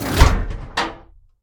gun-turret-deactivate-01.ogg